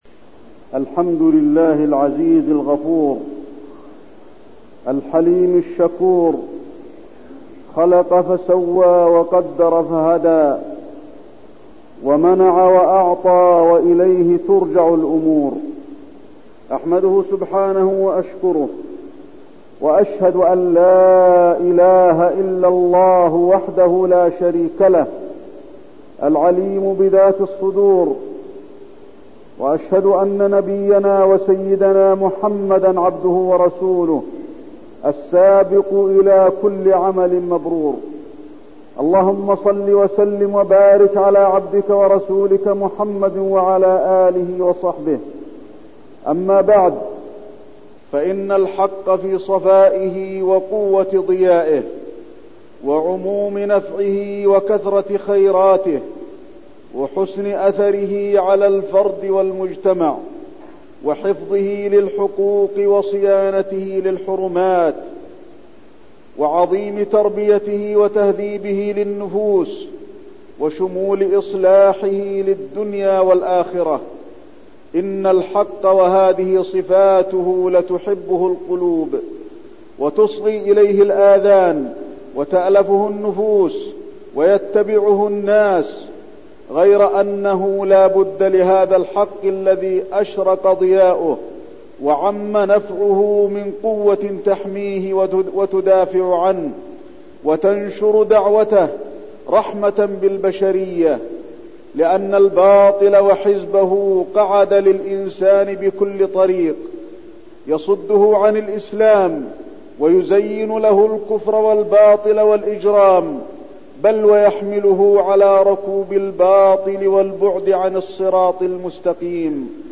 تاريخ النشر ٢٣ ربيع الأول ١٤١١ هـ المكان: المسجد النبوي الشيخ: فضيلة الشيخ د. علي بن عبدالرحمن الحذيفي فضيلة الشيخ د. علي بن عبدالرحمن الحذيفي الجهاد